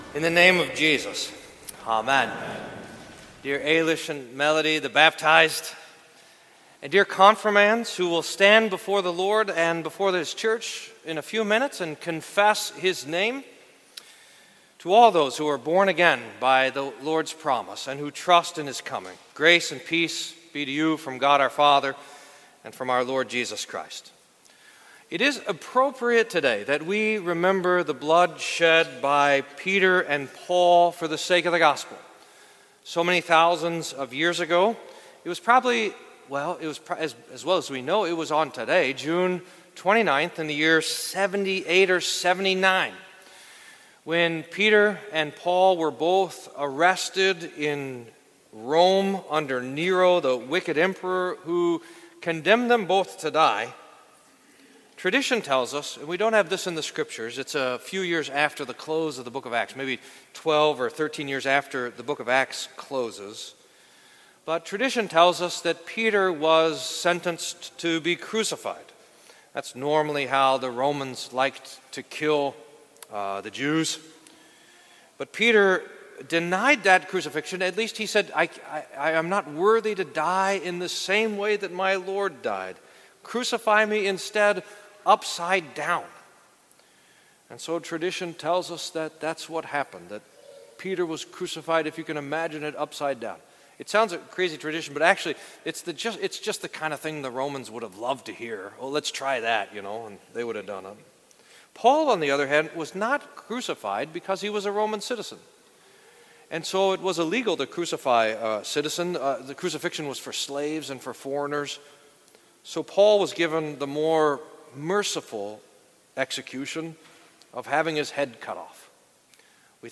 Sermon for St Peter and St Paul